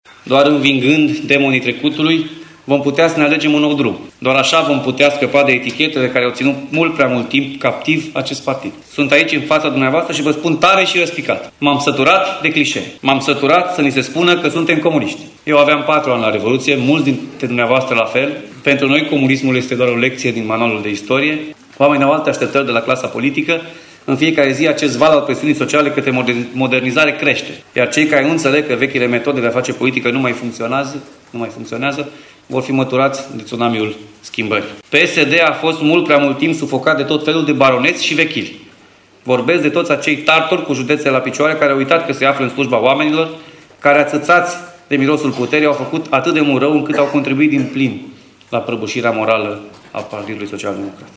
Social-democrații timișeni și-au ales, în cadrul unei conferințe extraordonare noua conducere a filialei județene.
simonis-discurs-alegeri-psd.mp3